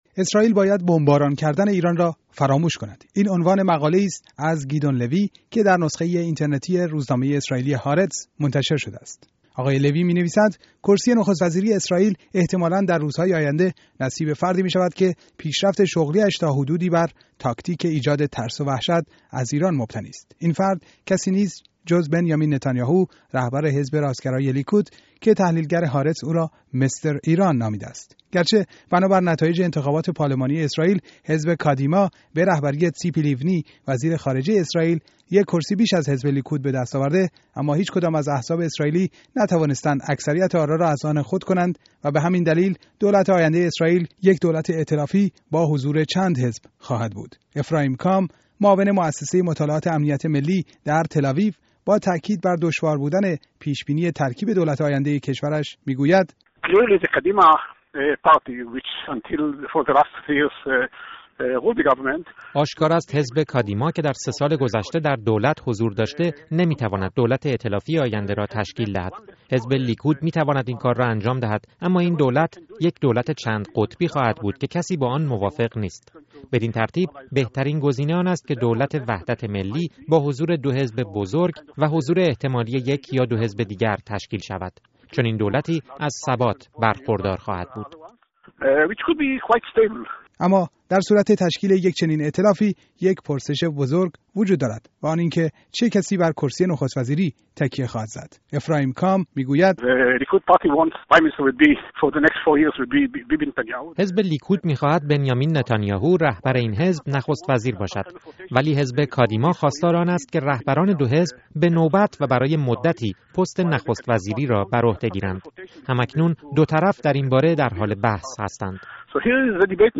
گزارش رایویی در همین زمینه